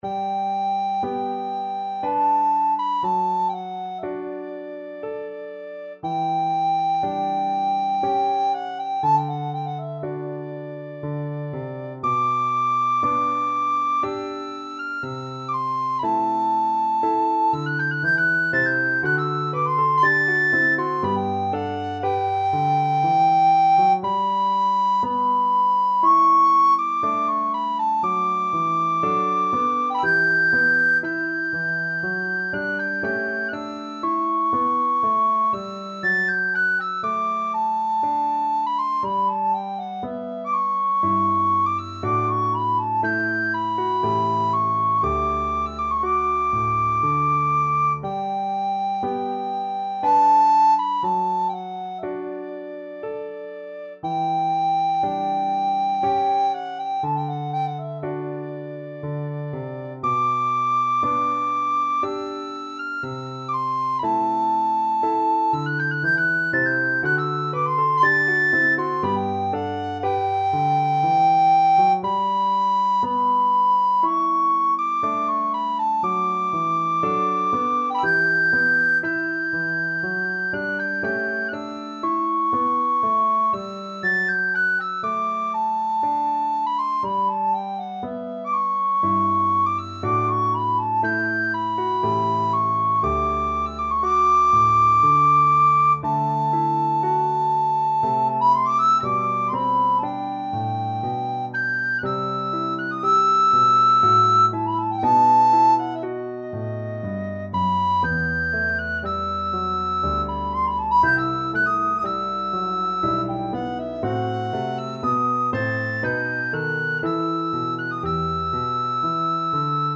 Free Sheet music for Soprano (Descant) Recorder
3/4 (View more 3/4 Music)
Classical (View more Classical Recorder Music)